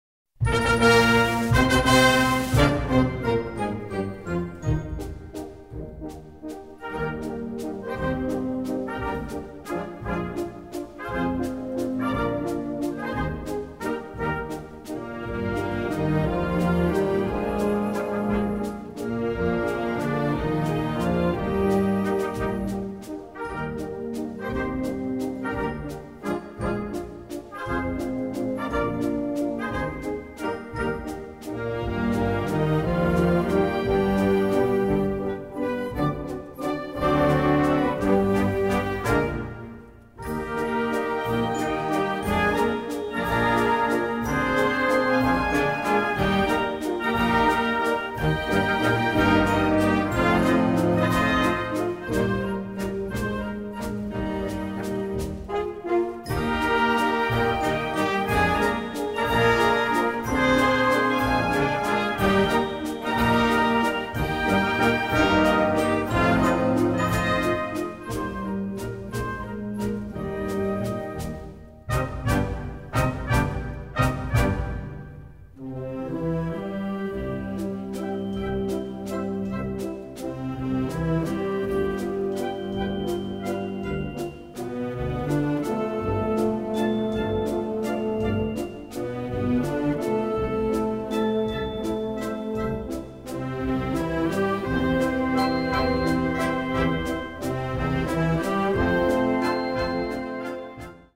Gattung: Walzerpotpourri
Besetzung: Blasorchester